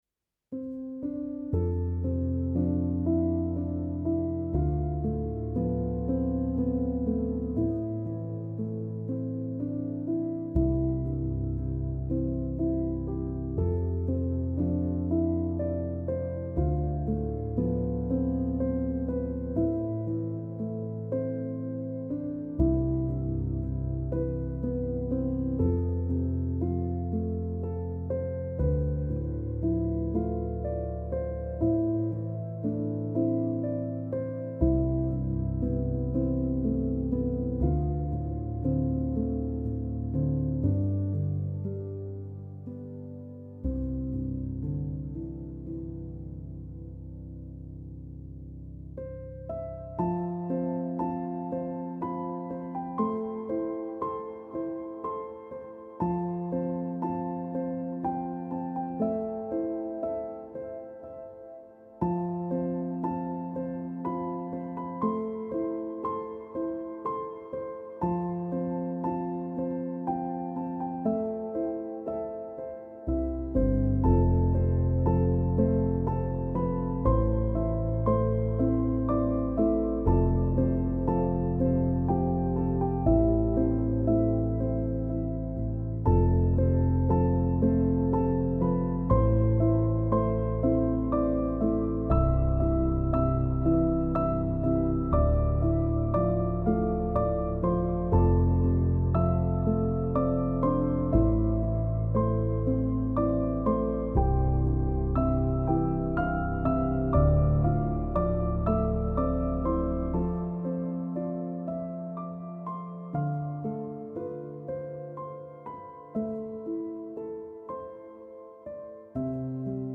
پیانو
سبک پیانو , مدرن کلاسیک , موسیقی بی کلام
تکنوازی پیانو